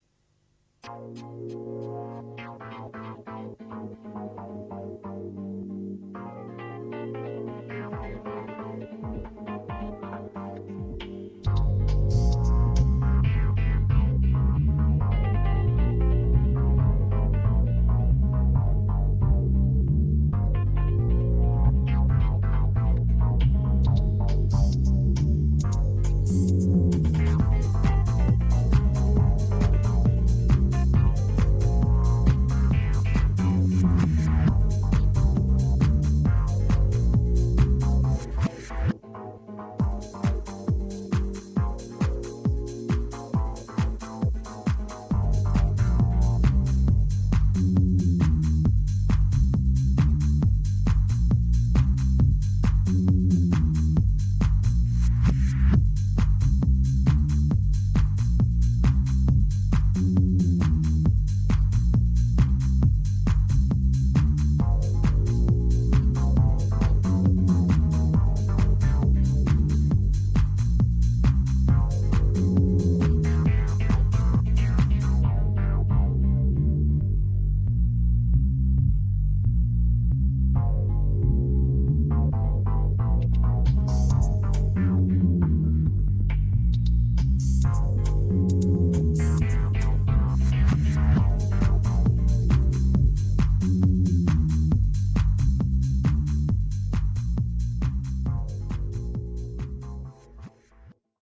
Since I have limited space on the server, the presented songs are only lower quality real audio samples.
AT THE FAST SPEED Garage style track